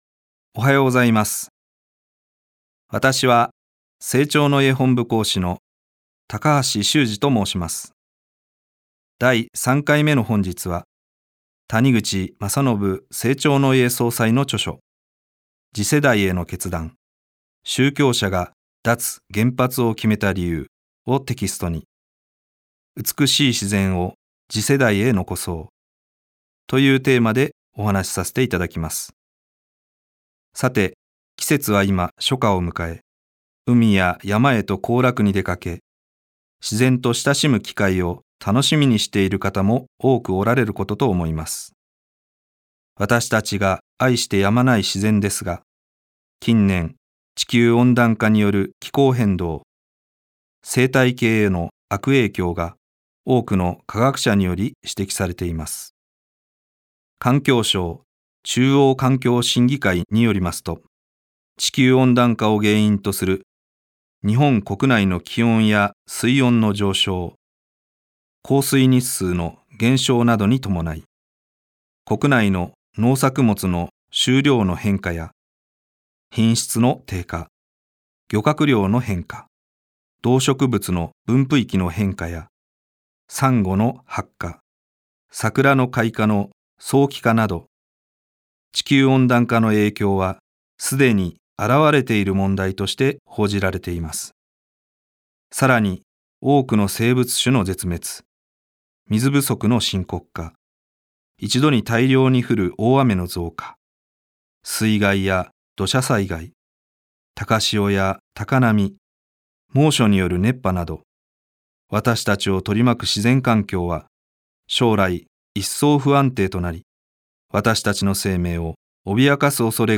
生長の家がお届けするラジオ番組。
生長の家の講師が、人生を豊かにする秘訣をお話しします。